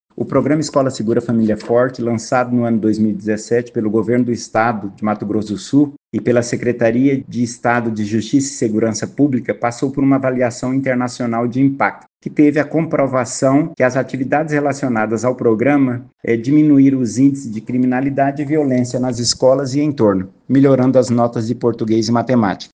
Em entrevista ao programa da FM Educativa MS “Agora 104”